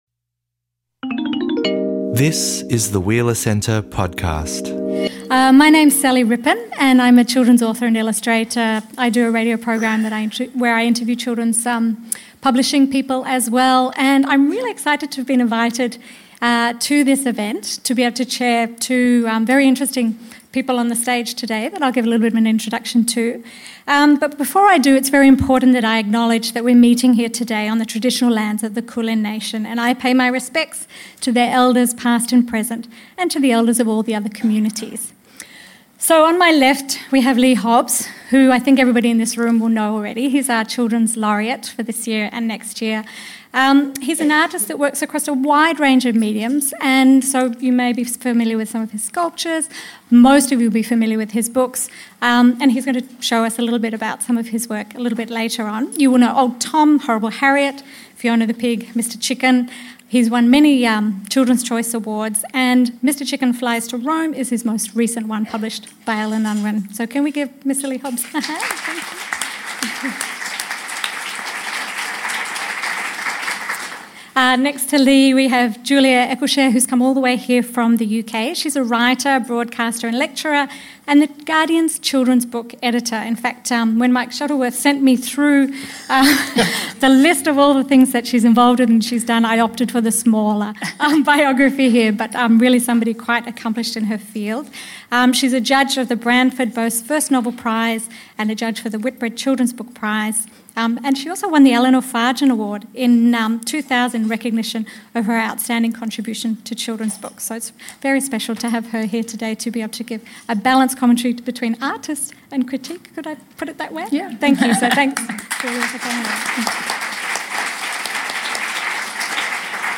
At this grown-up discussion about books for little people, we take a close-up look at children’s literature today, with some of the best in the business.
Tune in for some answers to these and other questions, in a lively conversation about kids, creativity and the business of books.